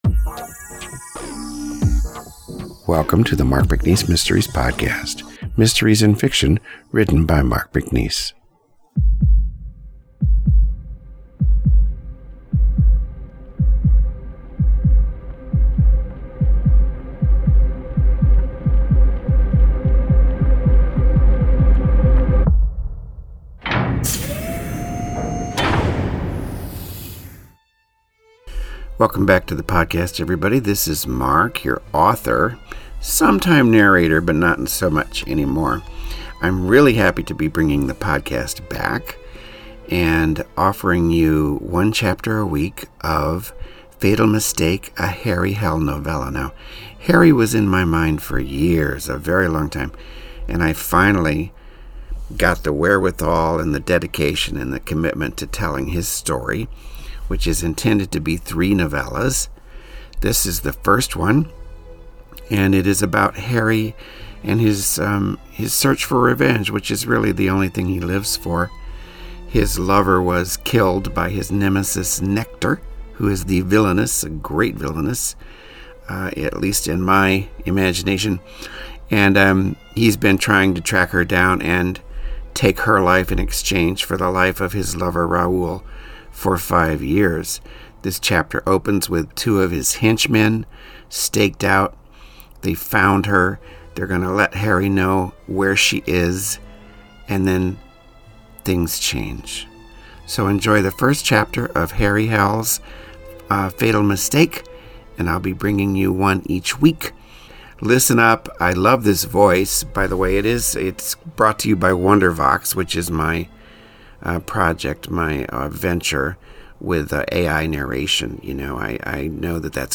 Narration provided by Wondervox.
Now I think it sounds terrific. Yes, it’s AI/synthetic voice.